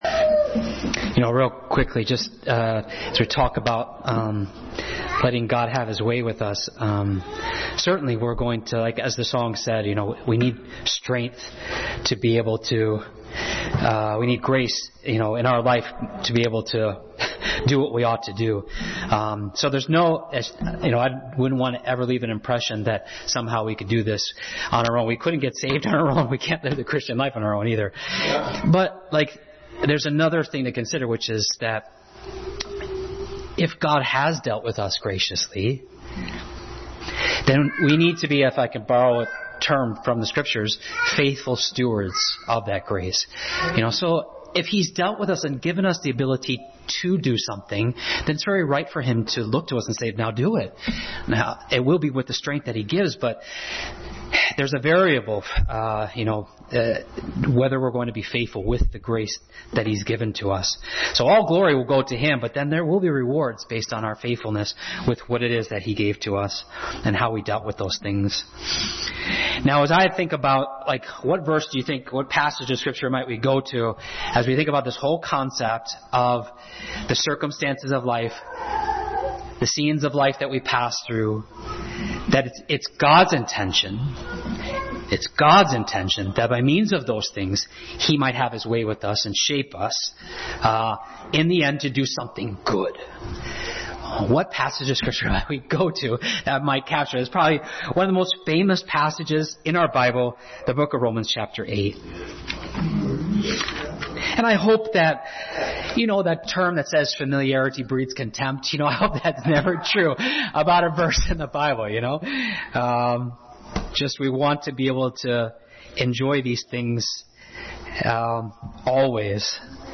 Passage: Romans 8:28-29 Service Type: Family Bible Hour